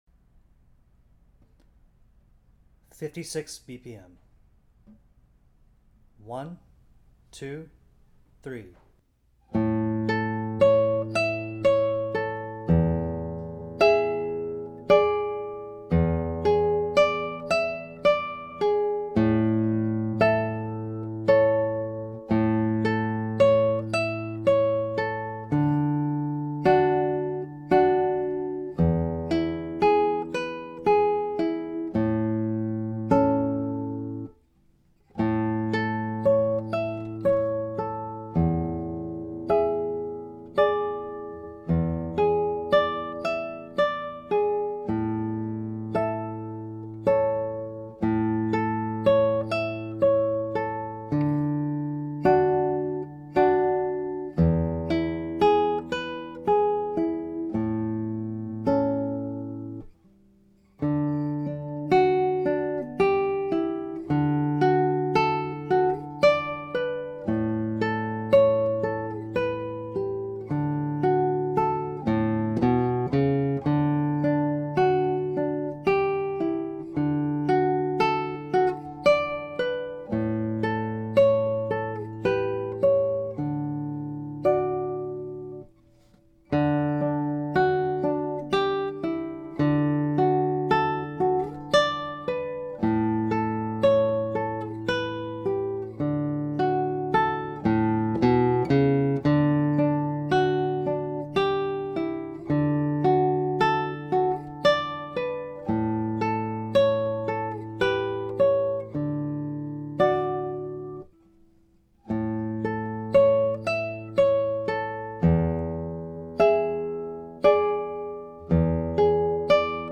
This mazurka for two guitars is a lively and fun dance that begins in A Major and moves to D Major in the second section before returning to the first section to end.
Mazurka (Duet) Audio Backing Tracks
Guitar 1, 56bpm: